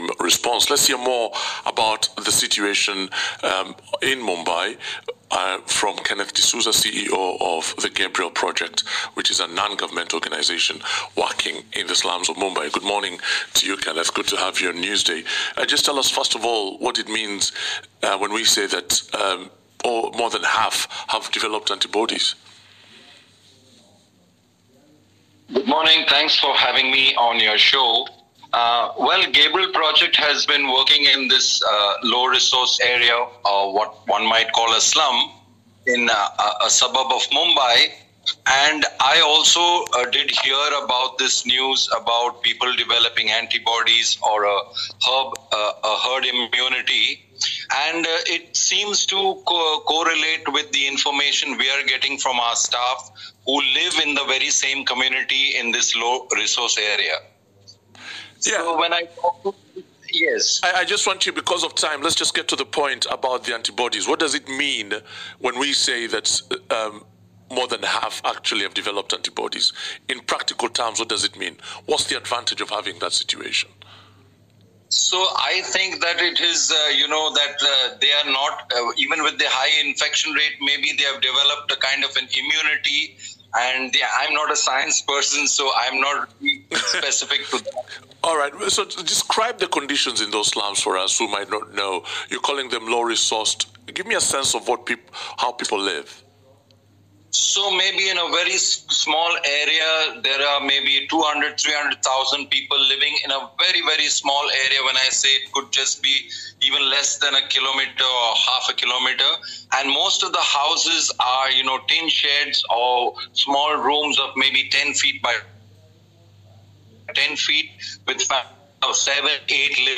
BBC World Service - News Day interview